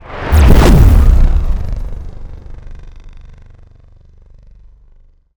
sci-fi_vehicle_thrusters_engage_02.wav